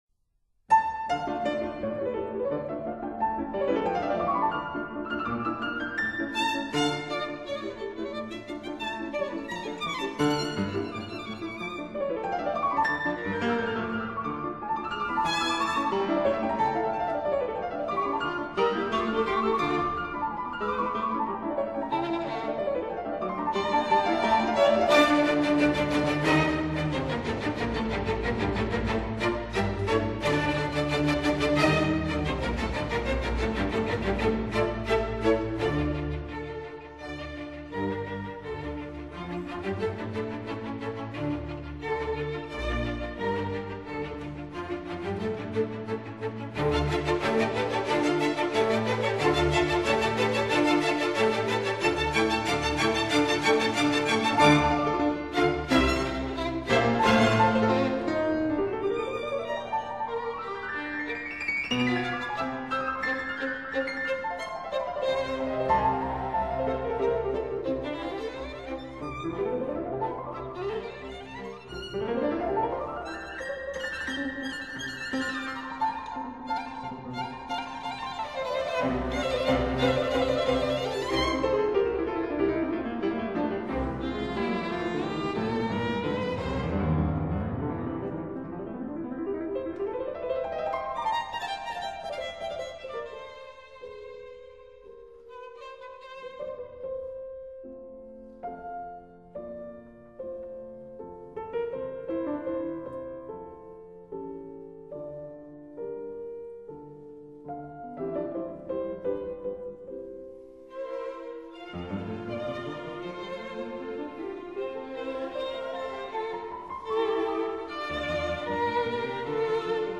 •(04) Concerto for Violin and String Orchestra in D minor